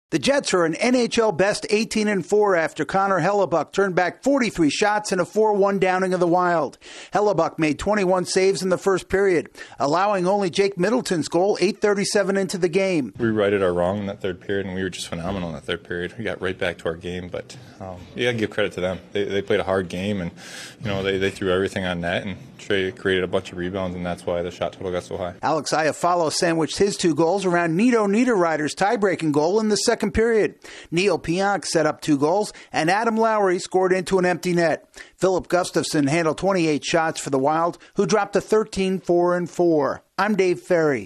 The Jets' goaltender is sharp again in a win over the Wild. Correspondent